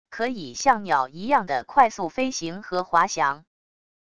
可以像鸟一样的快速飞行和滑翔wav音频